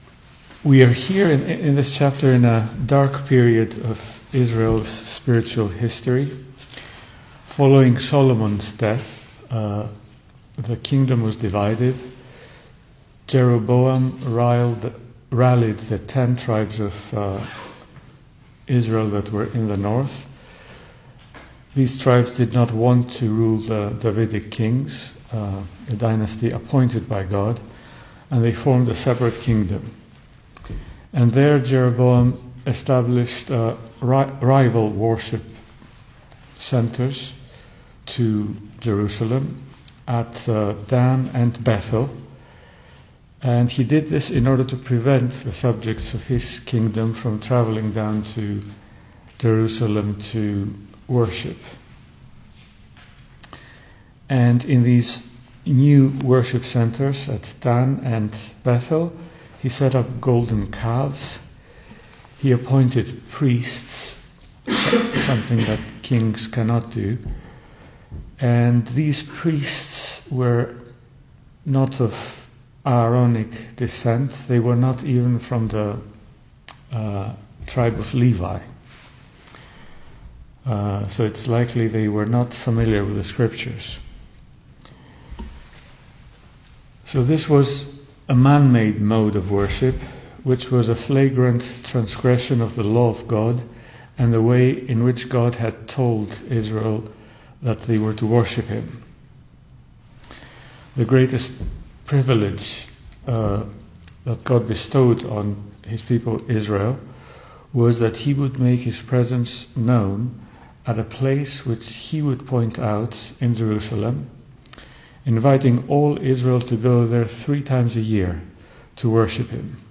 Service Type: Weekday Evening
Series: Single Sermons